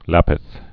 (lăpĭth)